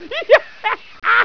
laugh5.wav